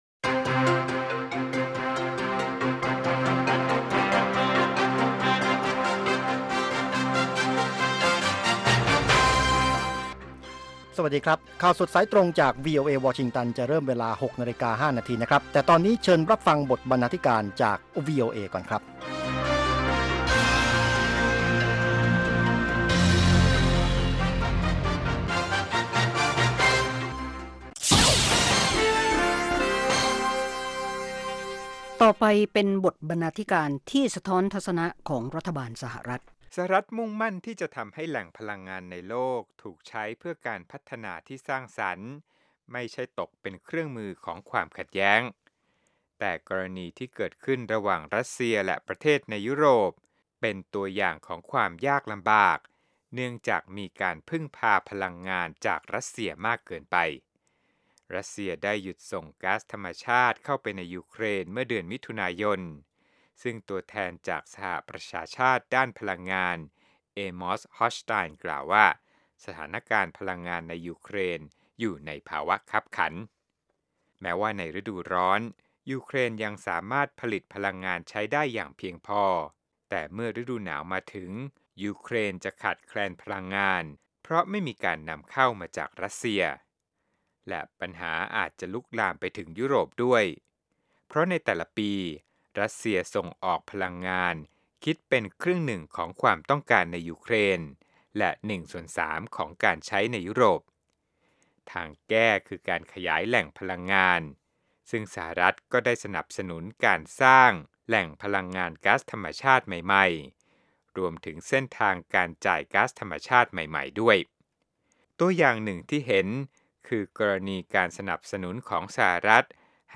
ข่าวสดสายตรงจากวีโอเอ ภาคภาษาไทย 6:00 – 6:30 น.